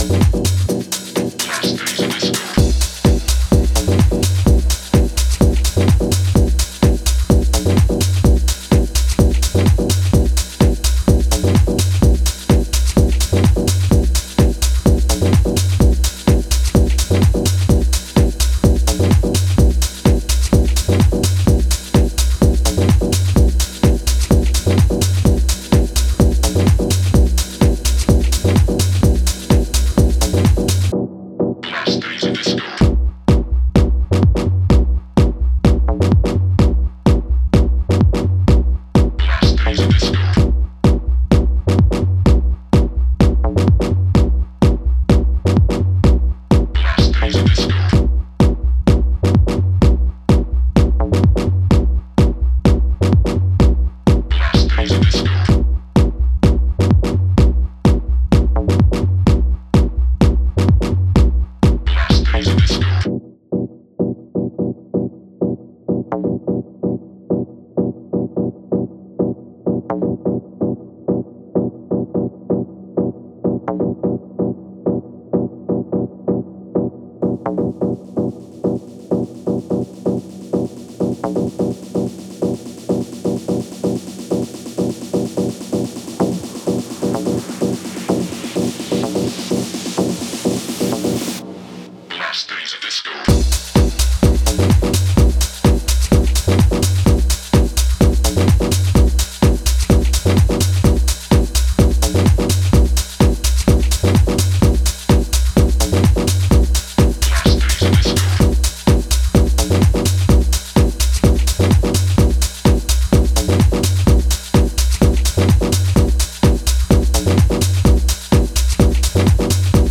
Styl: House, Techno